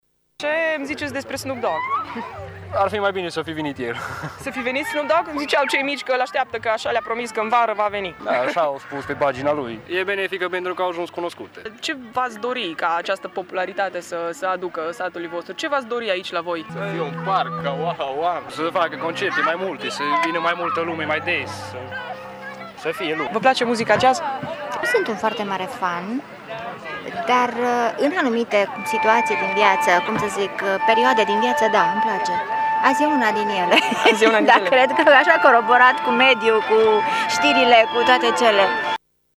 Atât localnici cât și turiști au fost încântați de organizarea evenimentului: